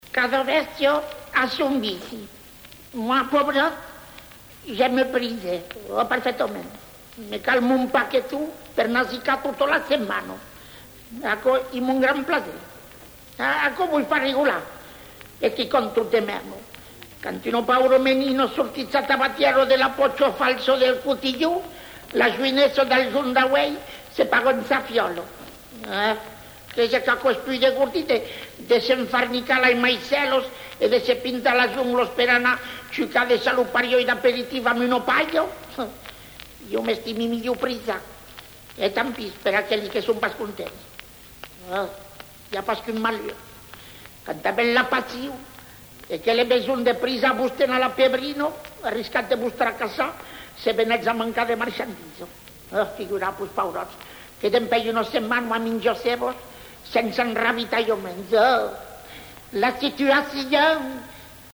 Genre sketch
acteurs jouant des histoires de Catinou et Jacouti en occita et français avec l'accent marseillais